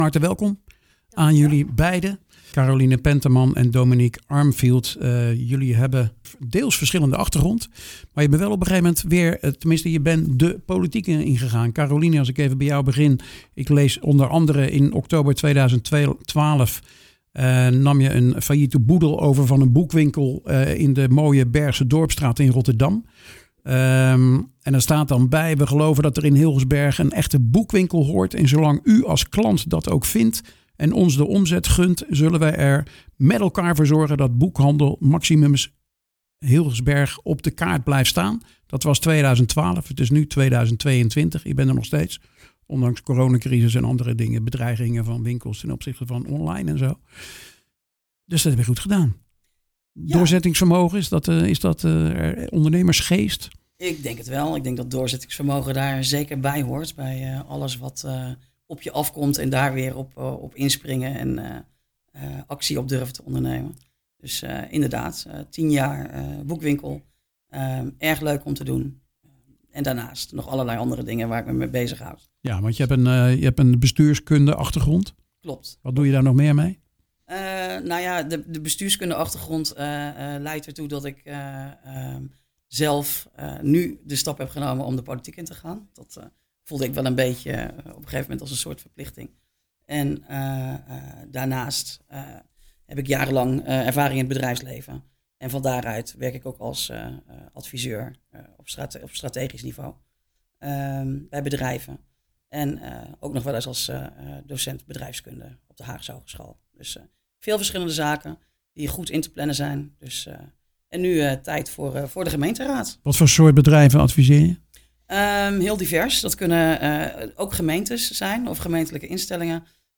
Vandaag te gast Caroline Penterman van de VVD en Dominique Armfield van Leefbaar Capelle.